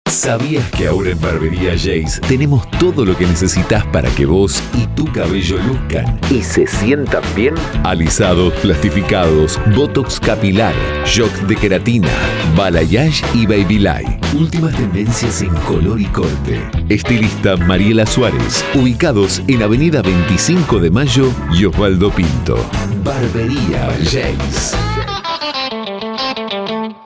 • Spot para barberia editado con Música blues